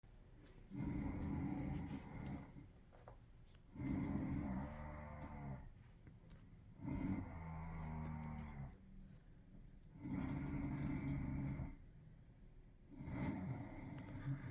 Sound Effects
Snoring Rat